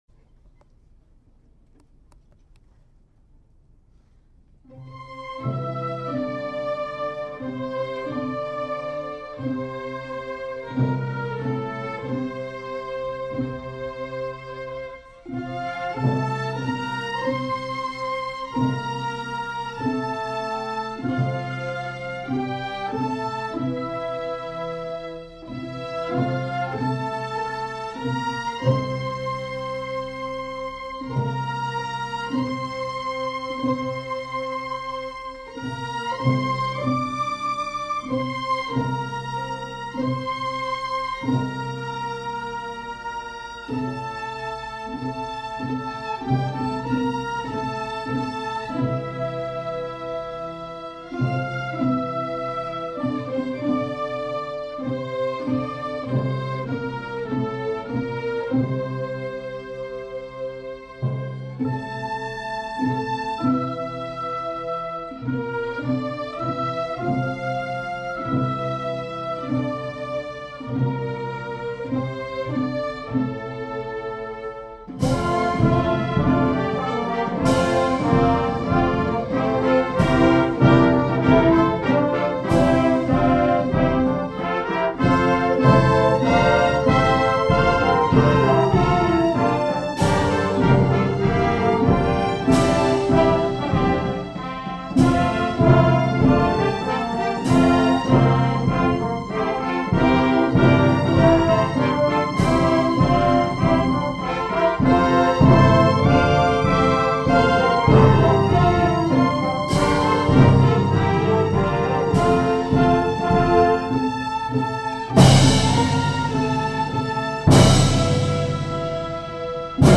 For Orchestra (Grade 3/4)